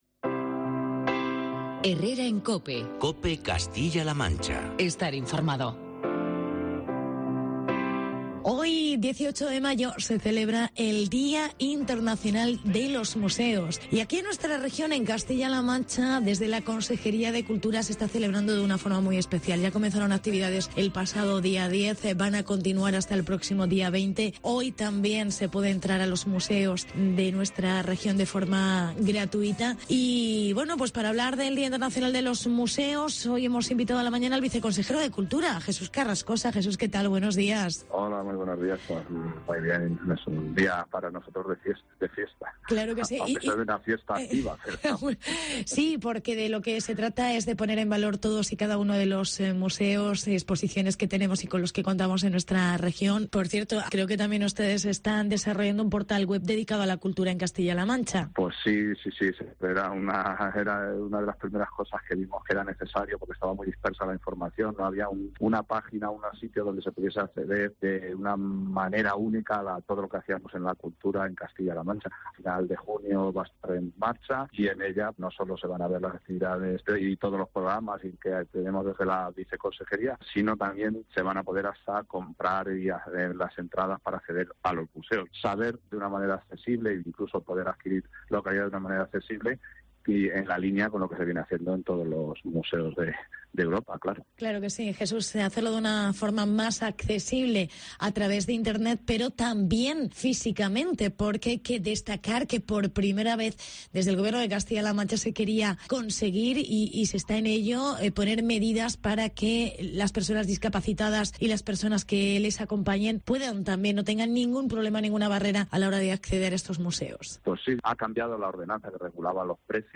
Entrevista con el viceconsejero Jesús Carrascosa